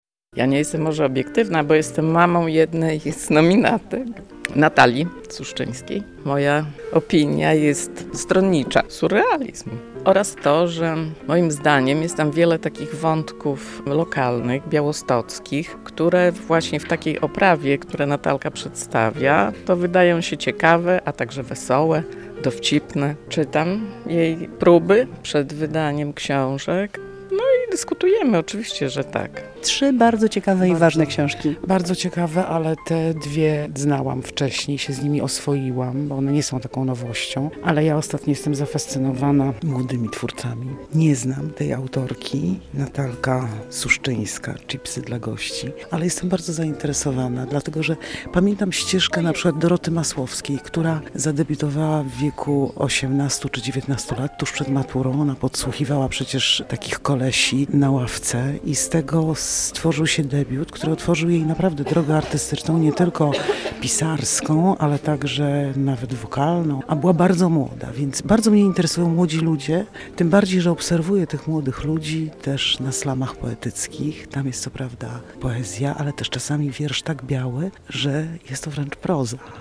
pytała czytelników o nominowanych do Nagrody Kazaneckiego